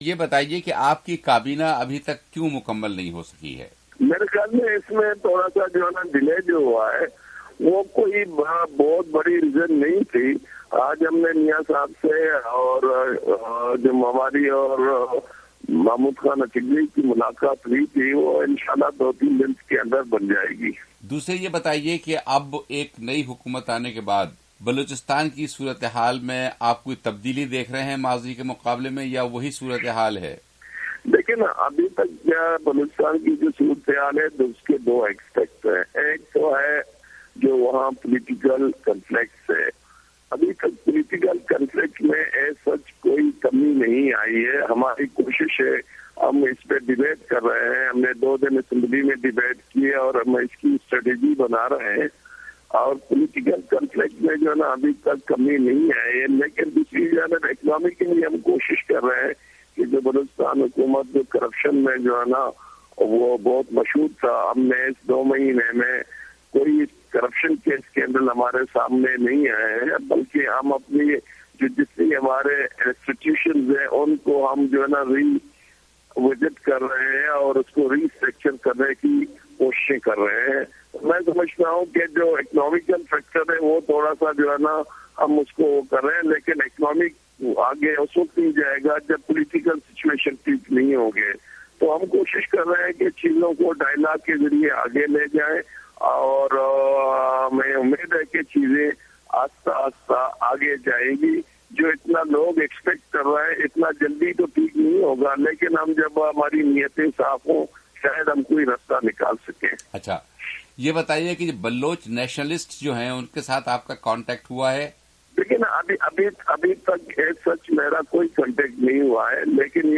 ڈاکٹر عبدالمالک سے خصوصی گفتگو